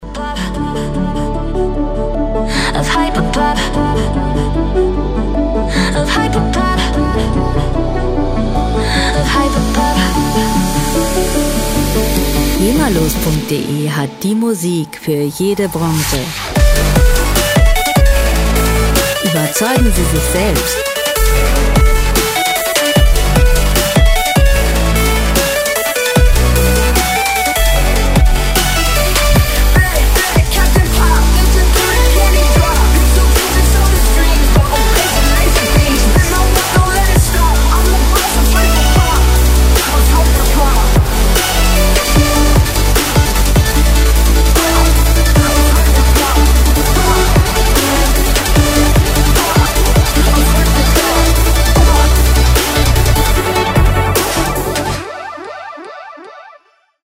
• Electro Pop